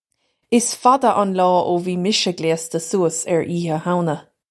Pronunciation for how to say
Iss fadda un law oh vee misha glay-sta soo-us urr Eeha Howna!
This is an approximate phonetic pronunciation of the phrase.